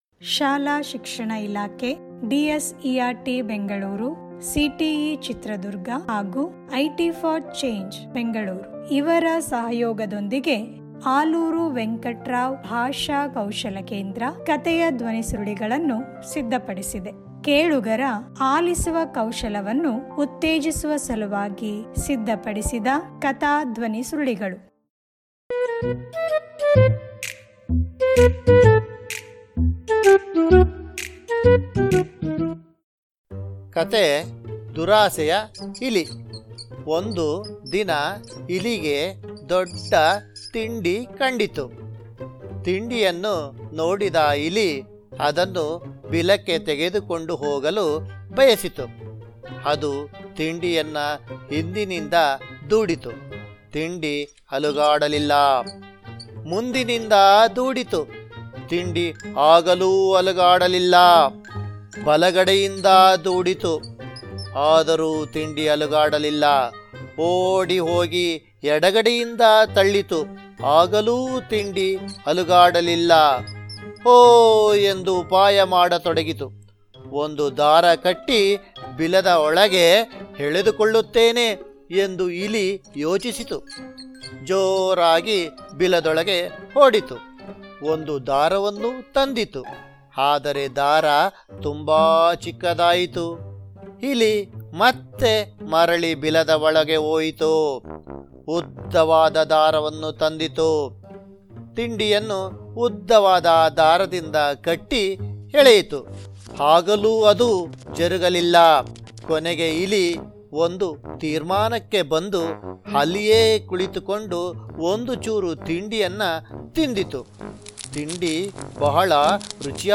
ಧ್ವನಿ ಕಥೆ ಲಿಂಕ್: